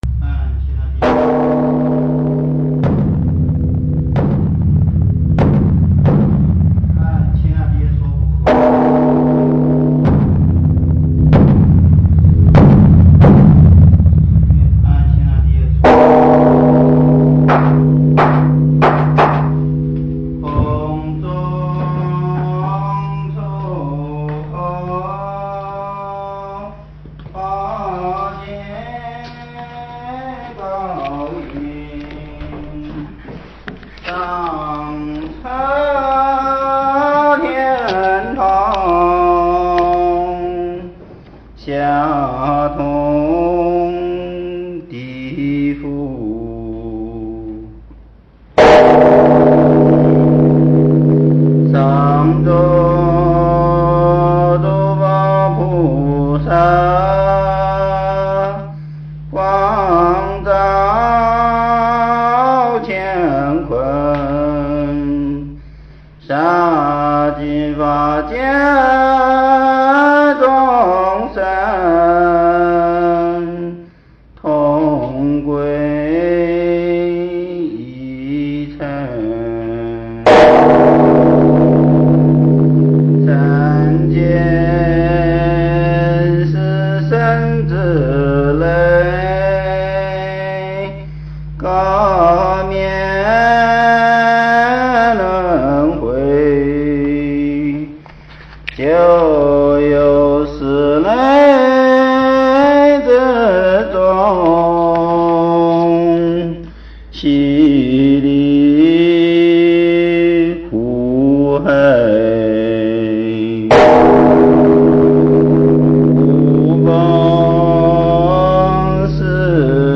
佛音 诵经 佛教音乐 返回列表 上一篇： 善天女咒 下一篇： 千手观音 相关文章 晨钟偈